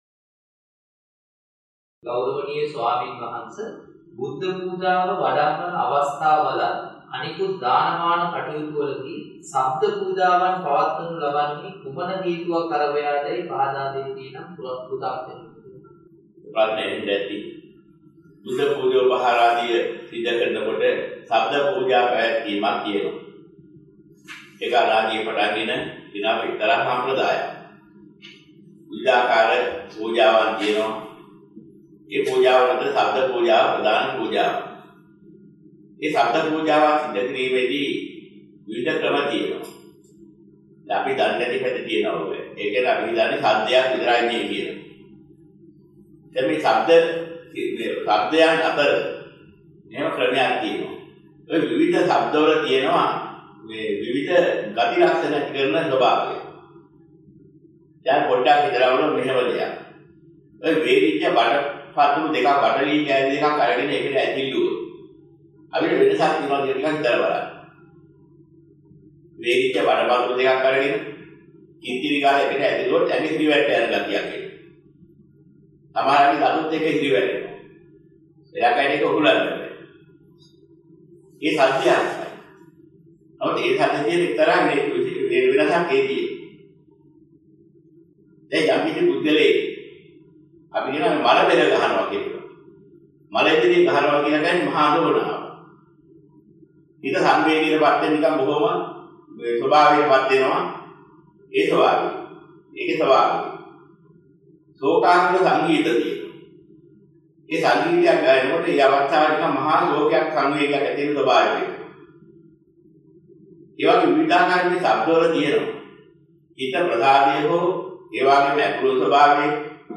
වෙනත් බ්‍රව්සරයක් භාවිතා කරන්නැයි යෝජනා කර සිටිමු 07:18 10 fast_rewind 10 fast_forward share බෙදාගන්න මෙම දේශනය පසුව සවන් දීමට අවැසි නම් මෙතැනින් බාගත කරන්න  (7 MB)